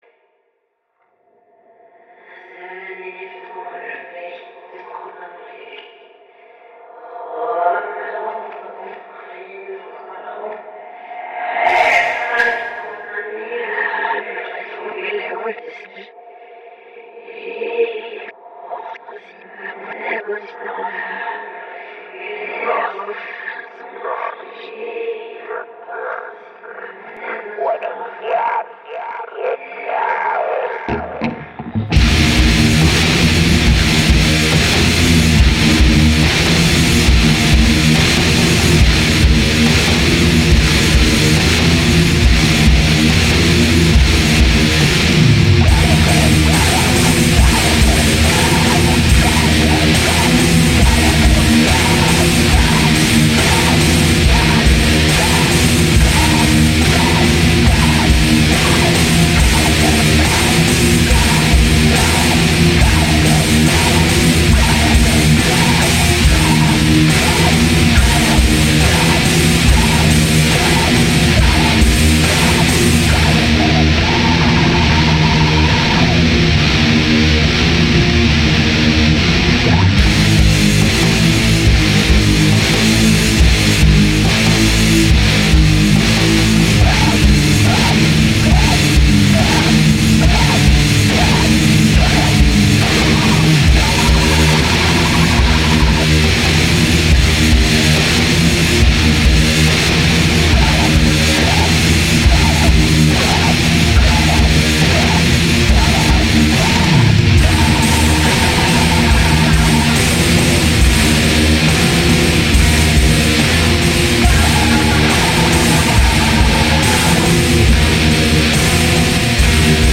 un mélange de black mid tempo et de death
guitare, basse, batterie, chant